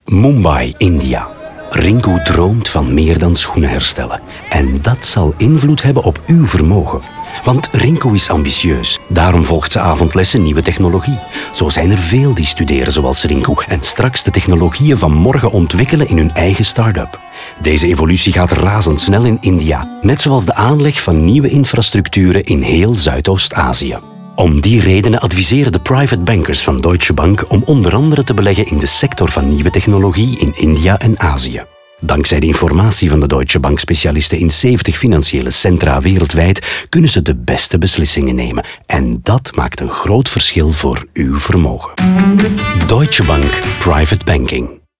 In de radiospots krijgen de verhalen van Rinku, Søren, Juan en Edward ruim de tijd, 45 seconden, om helemaal verteld te worden.